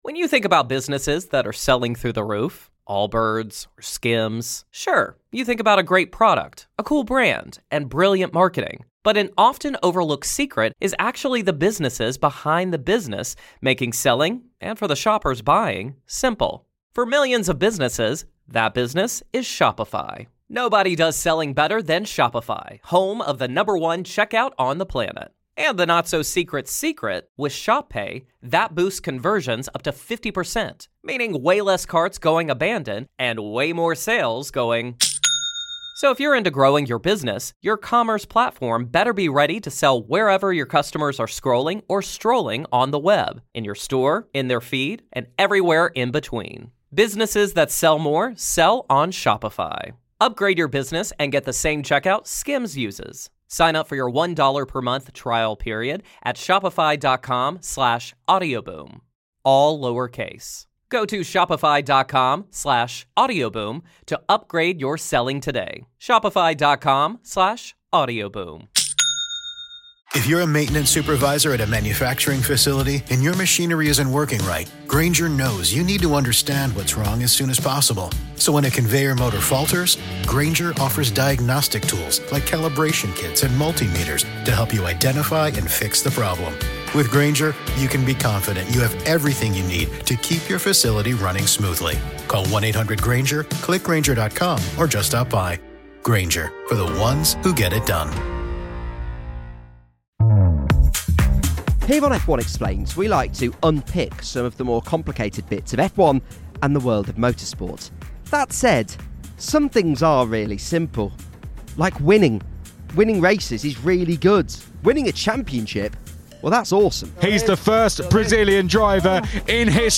in the paddock at the United States Grand Prix